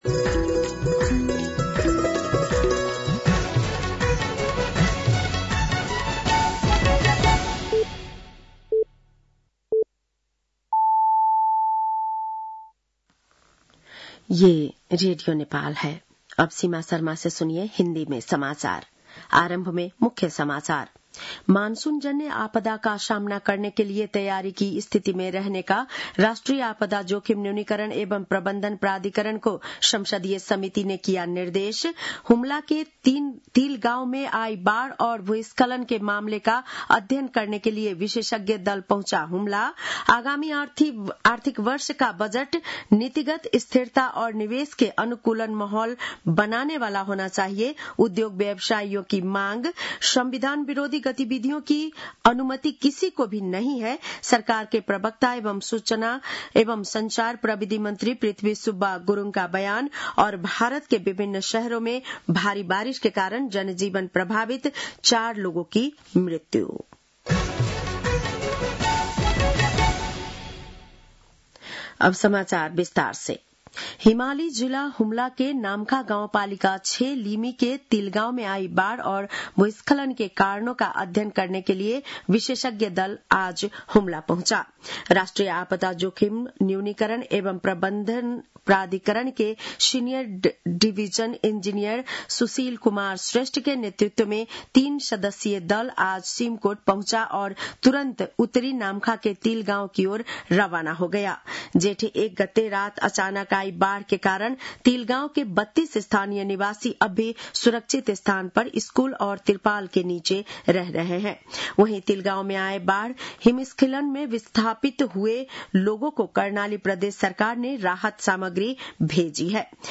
बेलुकी १० बजेको हिन्दी समाचार : ८ जेठ , २०८२
10-PM-Hindi-NEWS-1-3.mp3